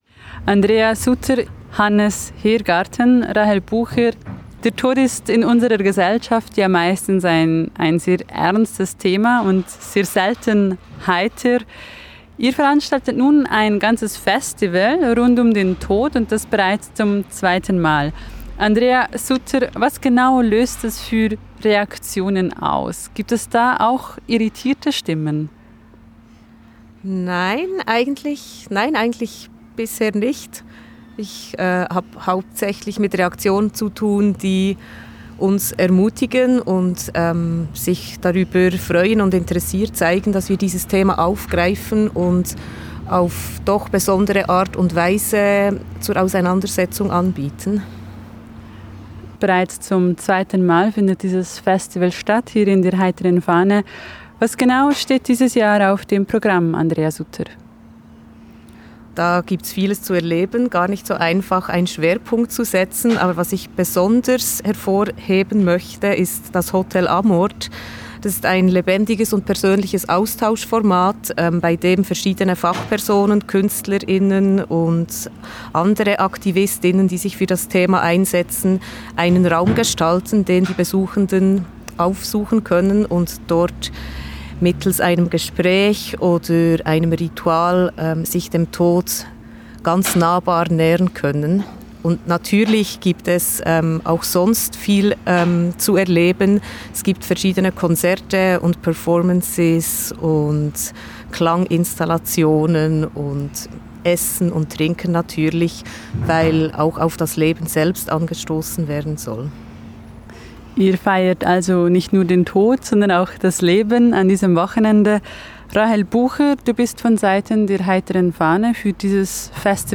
Interview Rabe Info